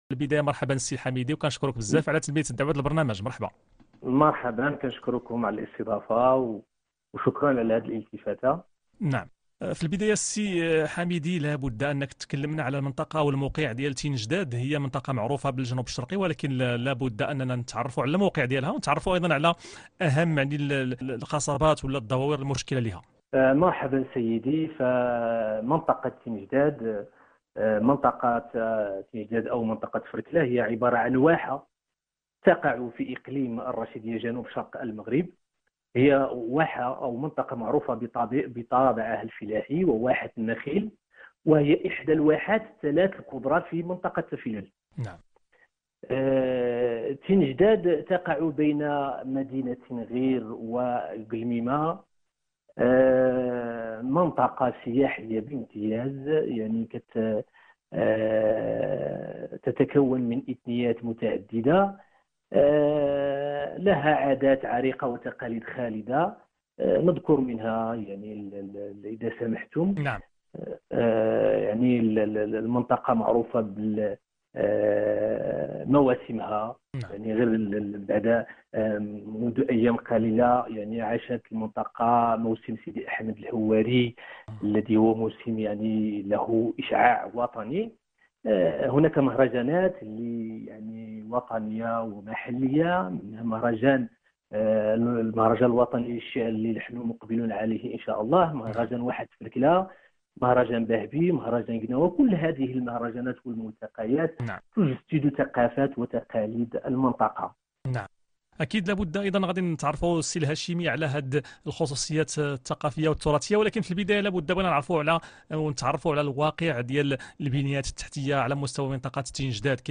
لقاء مع اذاعة مكناس الجهوية